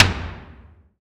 taiko-normal-hitwhistle.ogg